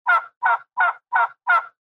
Звуки индюка
Вы можете слушать онлайн или скачать бесплатно их характерное голготание, клохтанье и другие крики в высоком качестве.
Звук манка для привлечения индюка на охоте